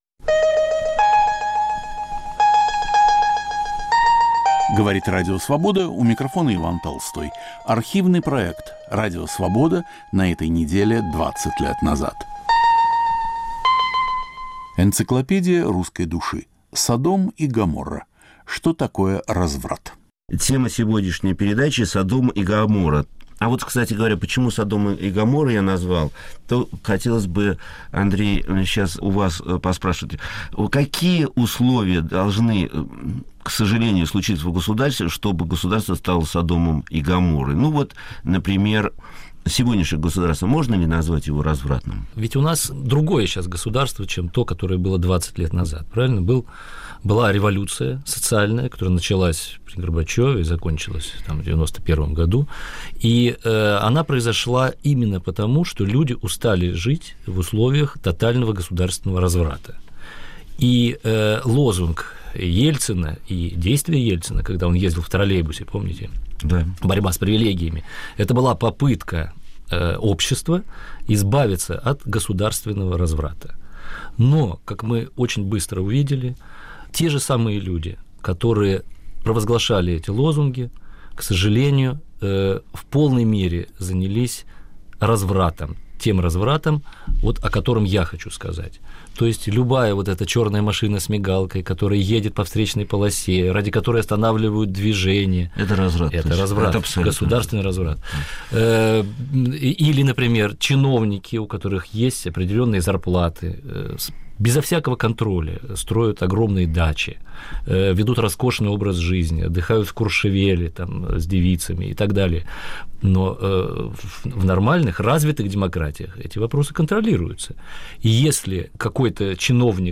Понятие разврата в разных культурах и в представлениях каждого человека. В студии социолог Игорь Кон и литератор Андрей Мальгин. Автор и ведущий Виктор Ерофеев.